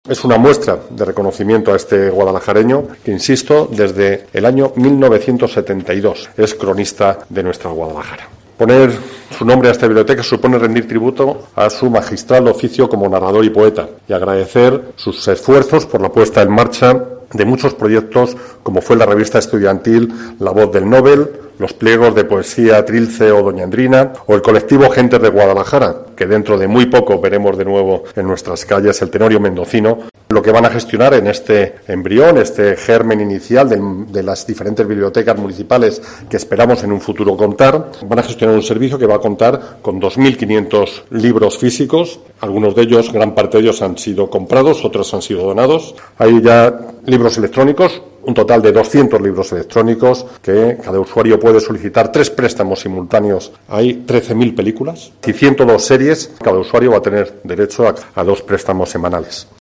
Antonio Román, Alcalde de Guadalajara